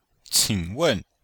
qing3wen4.mp3